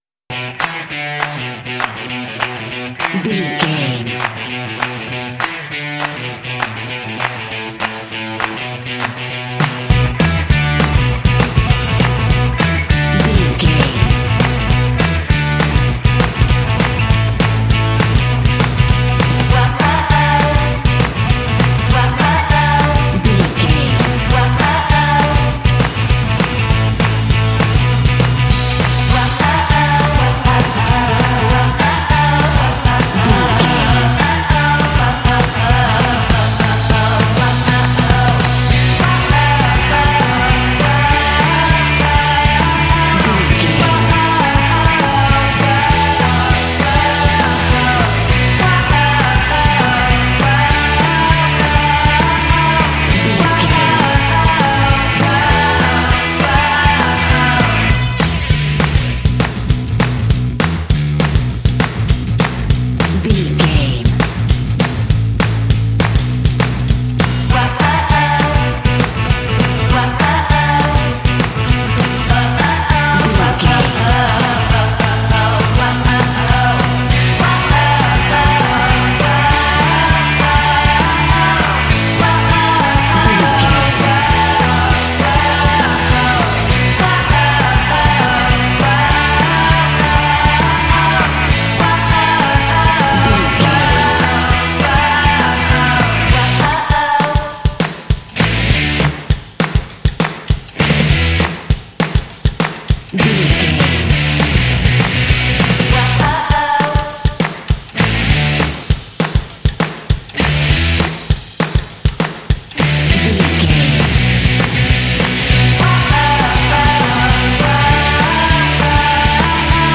Aeolian/Minor
driving
bouncy
energetic
electric guitar
drums
bass guitar
vocals
classic rock
alternative rock